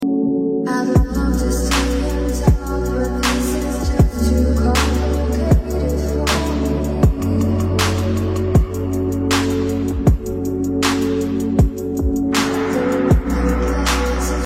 You Just Search Sound Effects And Download. tiktok funny sound hahaha Download Sound Effect Home My You Like It Upload By . 14 seconds 0 Downloads